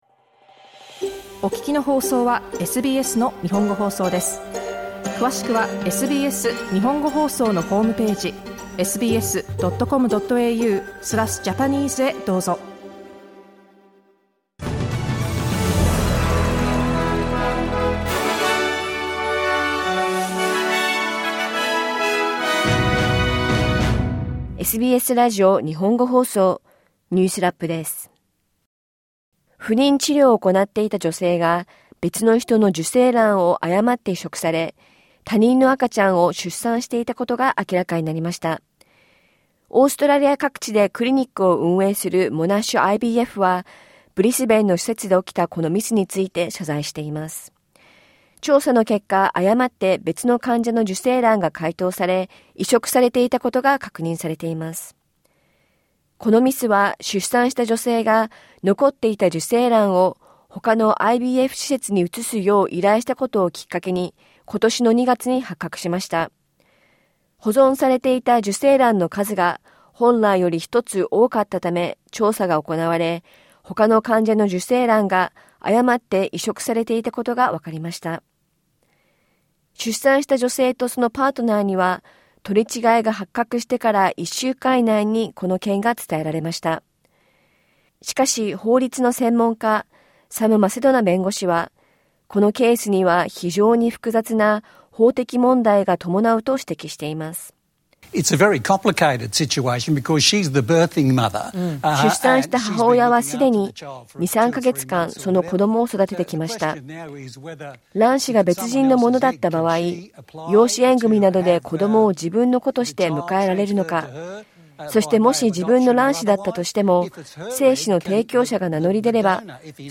1週間を振り返るニュースラップです。